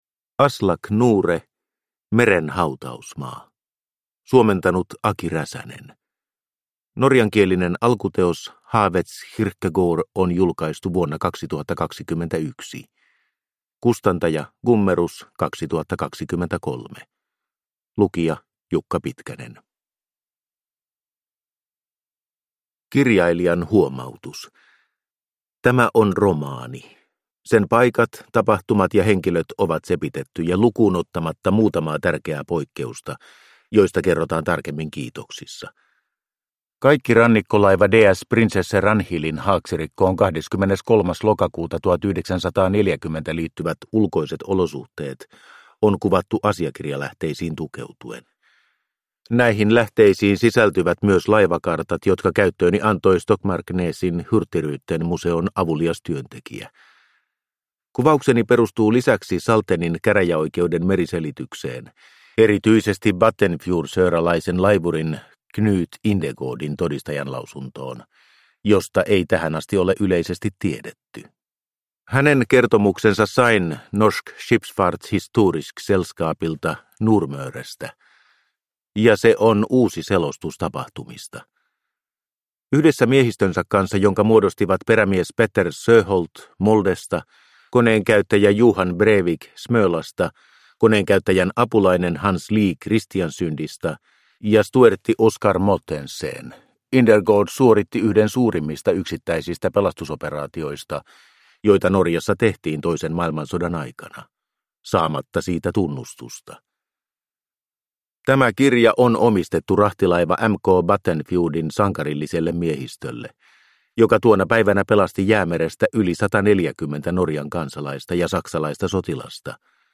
Meren hautausmaa – Ljudbok – Laddas ner